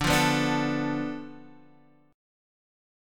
Dm6 chord